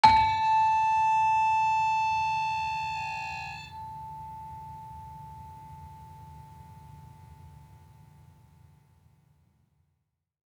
Gender-4-A4-f.wav